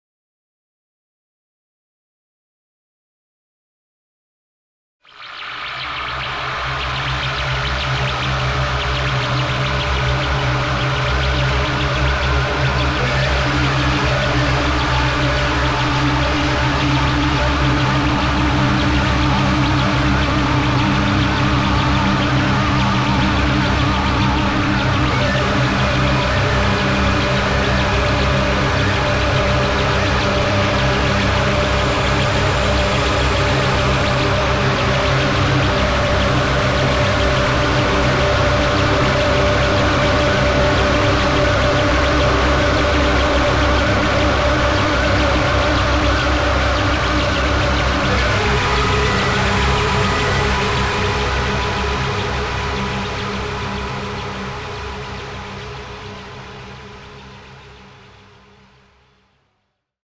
File under: Industrial / Experimental